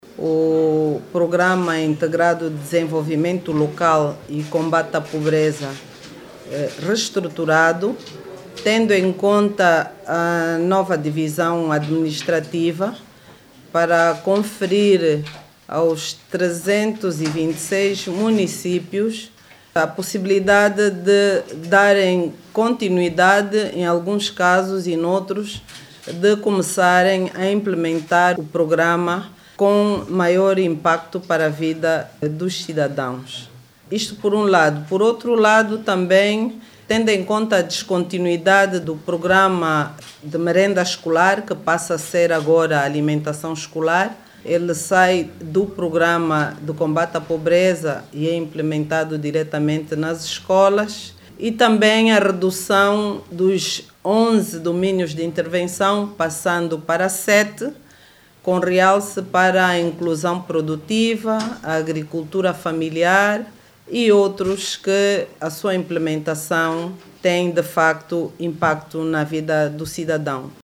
A Ministra da Acção Social, Família e Promoção da Mulher garantiu que o programa vai priorizar a inclusão produtiva e a agricultura familiar.
Ana Paula do Sacramento Neto explicou que o diploma mereceu uma restruturação para atender os 326 municípios, no quadro da nova divisão politico administrativa. De acordo com a ministra entre as alterações está igualmente a inclusão do programa de alimentação escolar que será agora implementado pelas escolas.